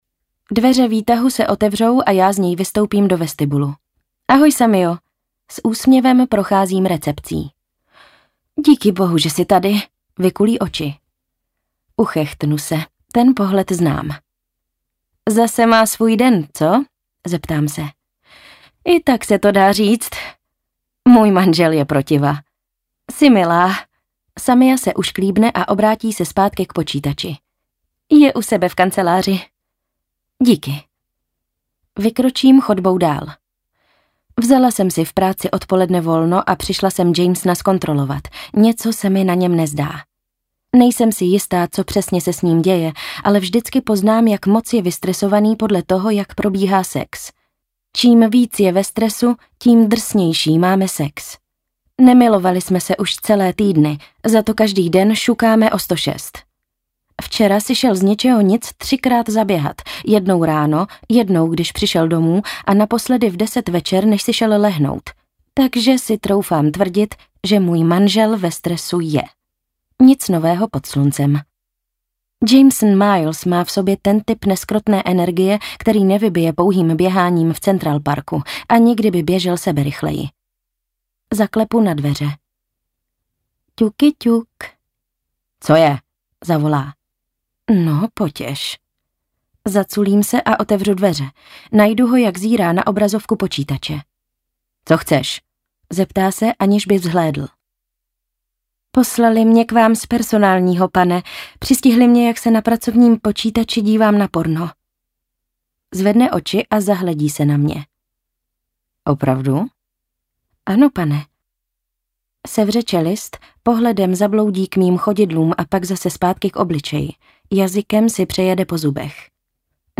Audiokniha pre dospelých